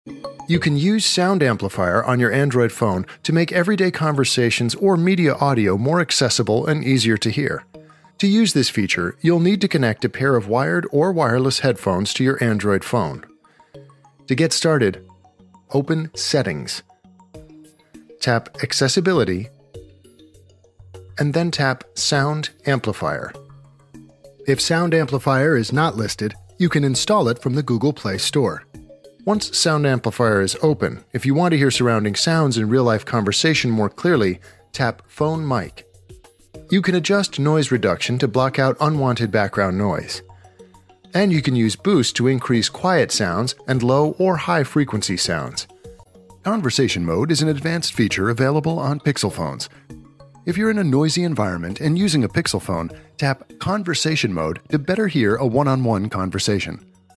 AI-powered Sound Amplifier Amplify Voices in Noisy Environment